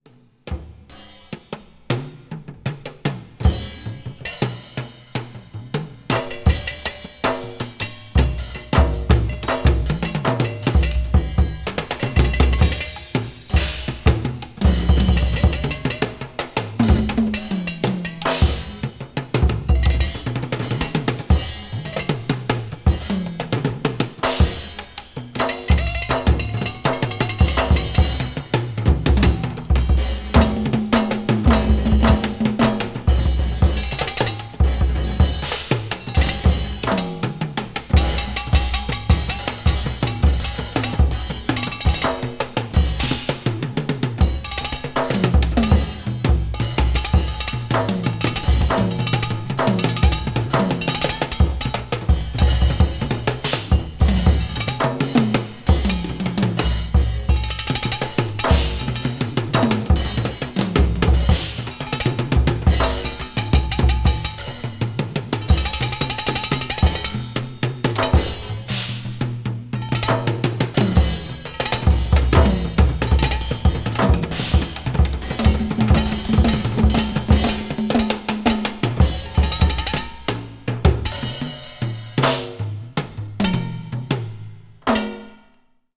media : EX/EX(some slightly noises.)
avant garde   experimental   free improvisation   free music